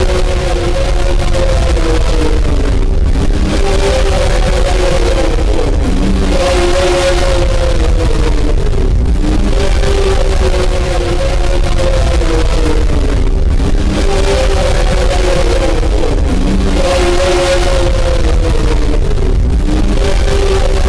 Audio_loserambience_(in-game).ogg